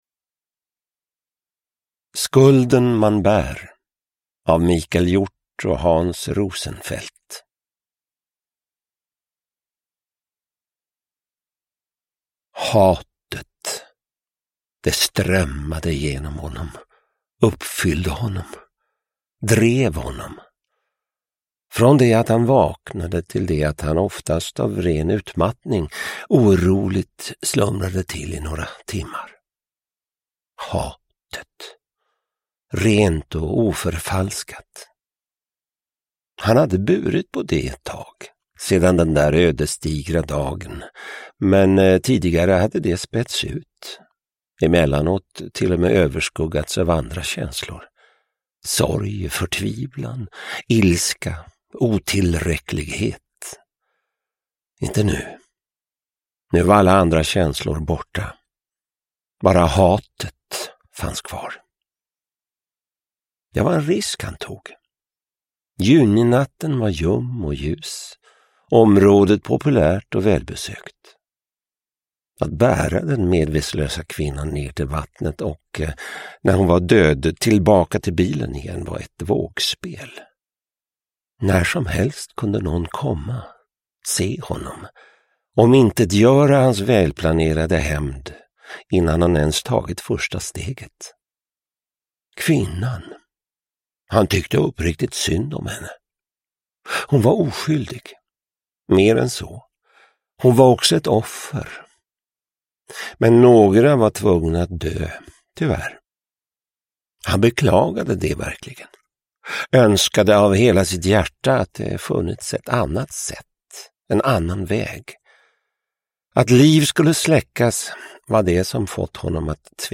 Skulden man bär (ljudbok) av Hans Rosenfeldt